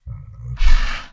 Minecraft Version Minecraft Version 1.21.5 Latest Release | Latest Snapshot 1.21.5 / assets / minecraft / sounds / mob / camel / ambient6.ogg Compare With Compare With Latest Release | Latest Snapshot
ambient6.ogg